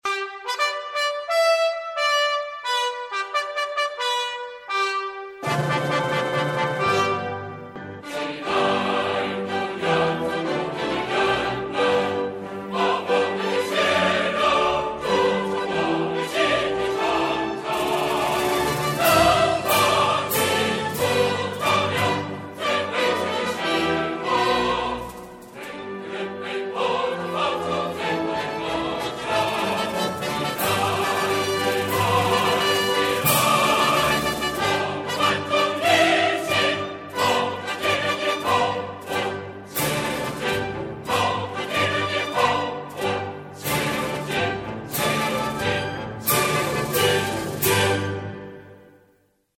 下をクリックすると歌詞付きの国歌が再生されます。